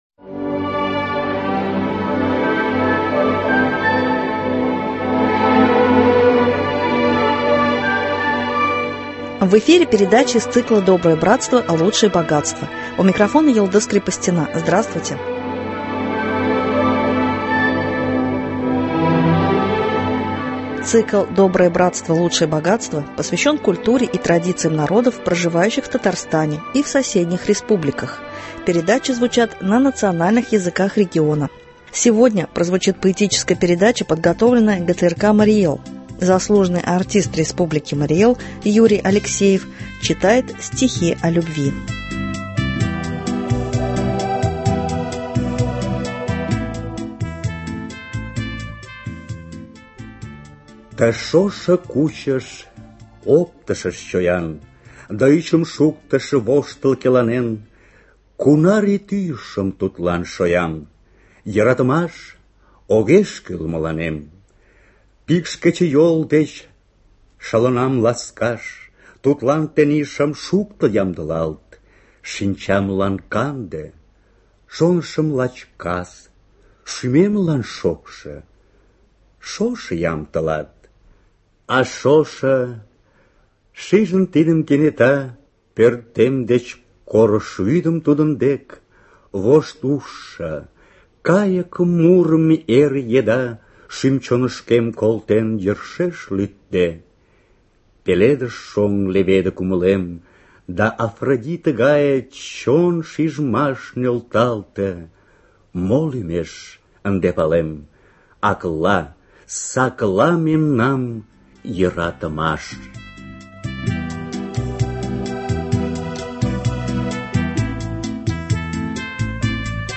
Стихи о любви ( литературно- музыкальная композиция на марийском языке).